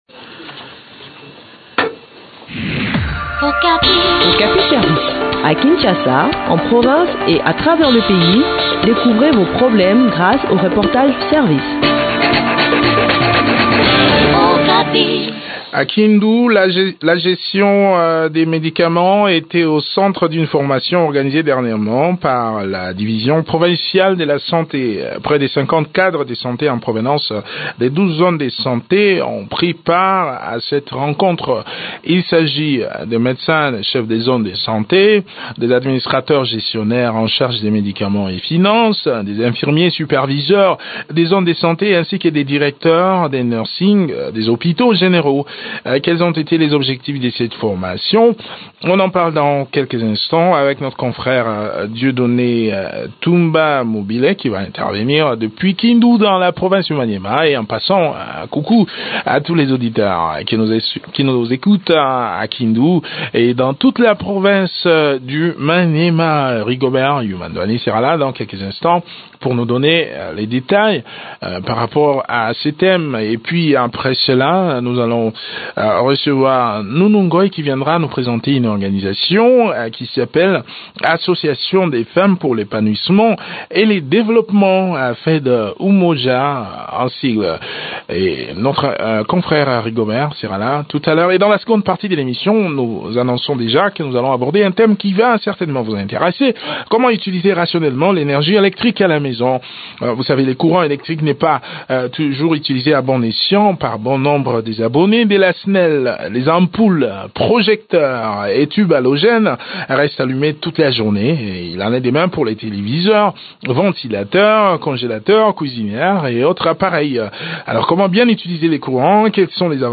Le point sur l’organisation de cette session de formation dans cet entretien